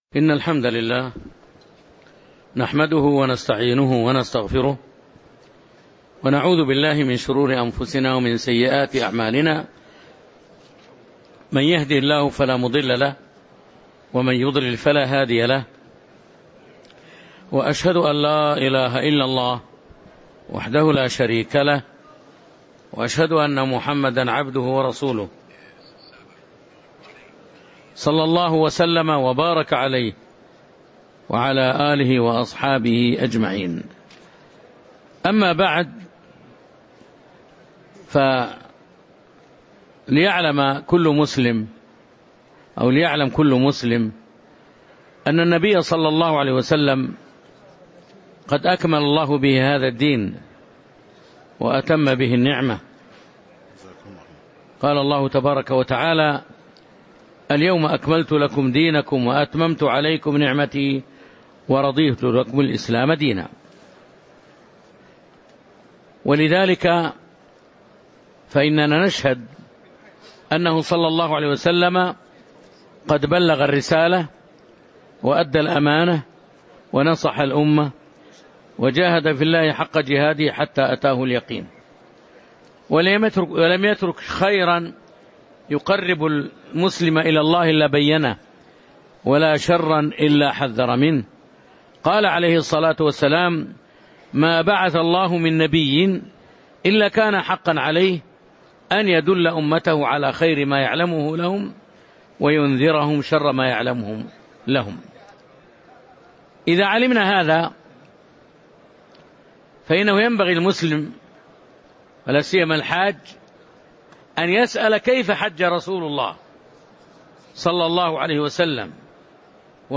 تاريخ النشر ١ ذو الحجة ١٤٤٤ هـ المكان: المسجد النبوي الشيخ